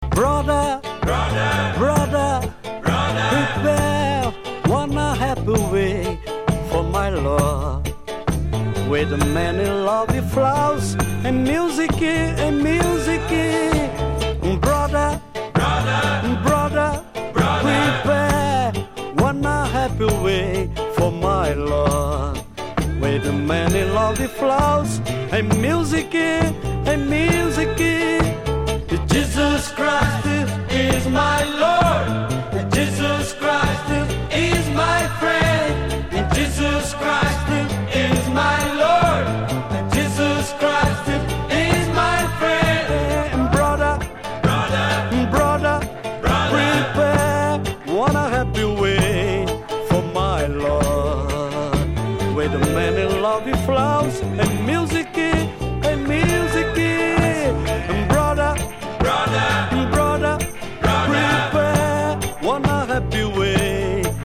Digitally remastered.
uma canção em samba lento